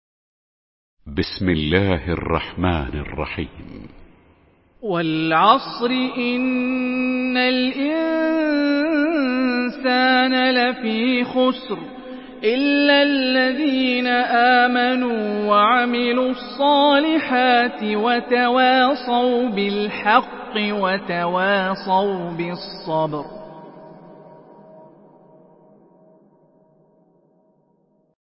Surah العصر MP3 by هاني الرفاعي in حفص عن عاصم narration.
مرتل حفص عن عاصم